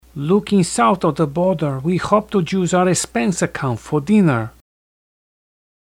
Spanish (Castilian & Colonial)
spanish.mp3